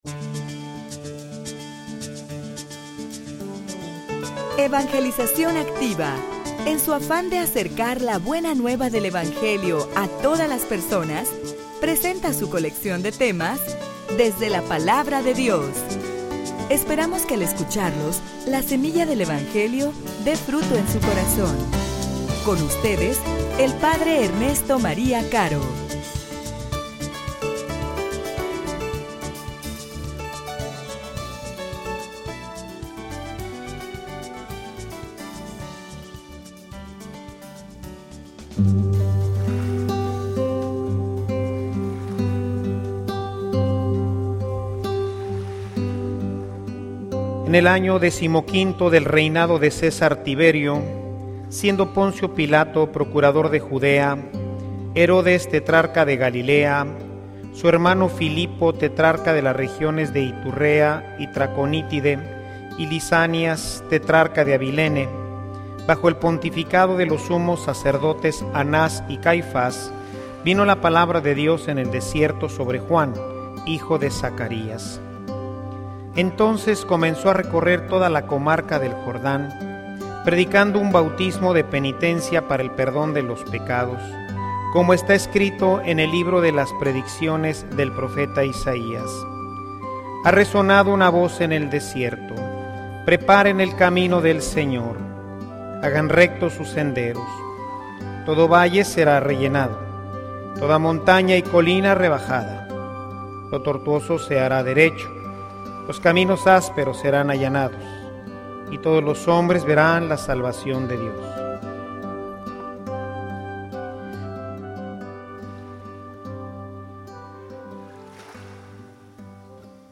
homilia_Emparejen_el_camino_del_Senor.mp3